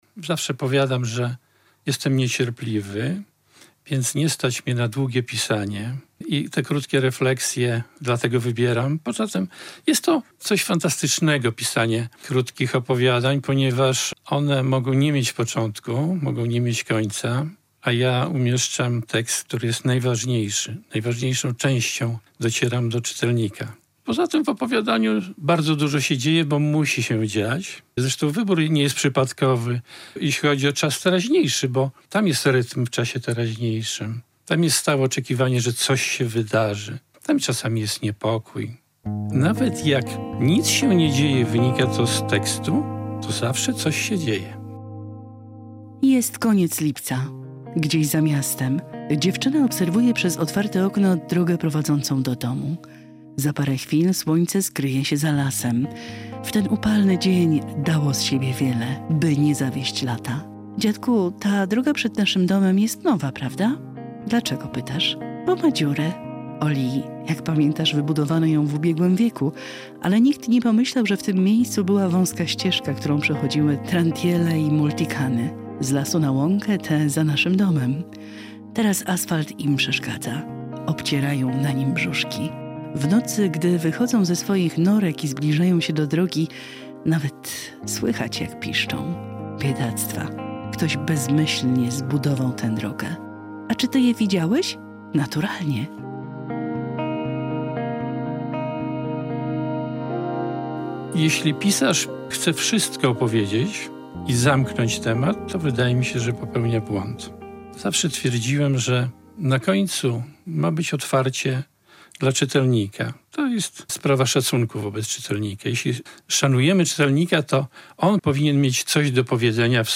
a o zbiorze opowiada autor - Wojciech Karpiński